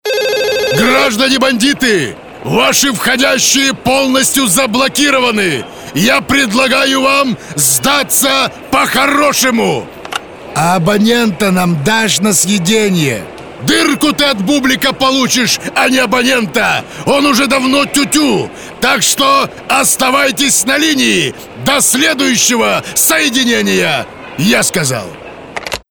Parodiya_na_Jeglova.mp3